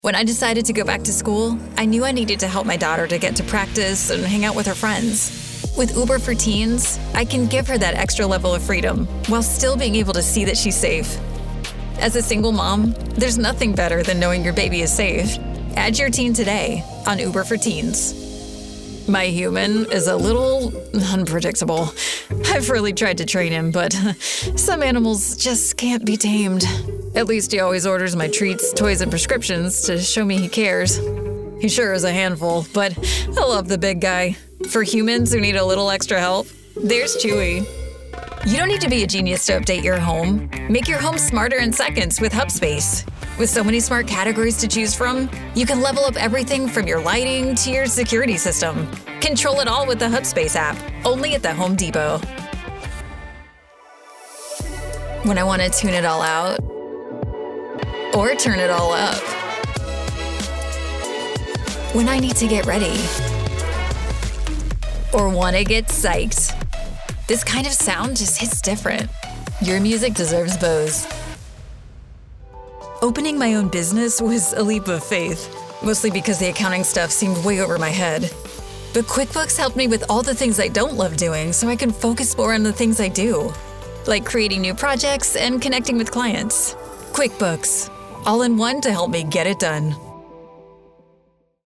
sehr variabel, hell, fein, zart
Mittel minus (25-45)
Comemrcial Demo
Commercial (Werbung)